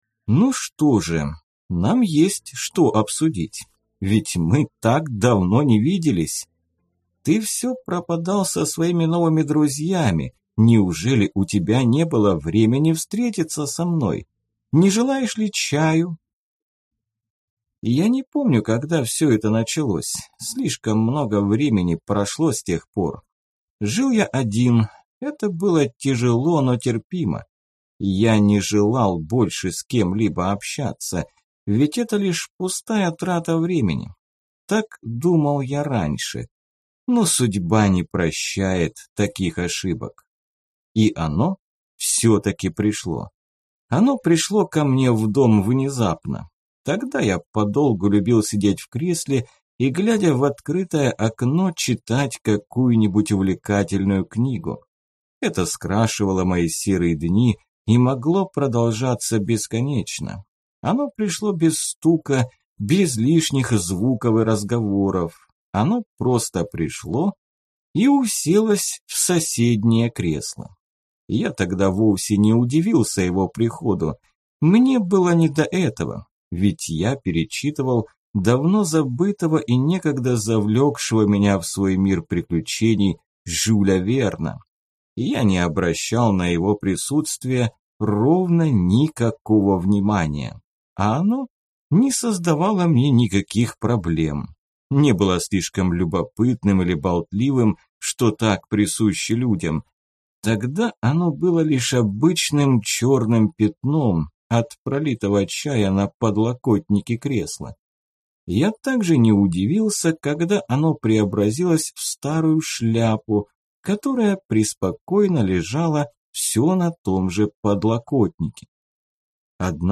Аудиокнига Когда наступает ночь. Сборник рассказов | Библиотека аудиокниг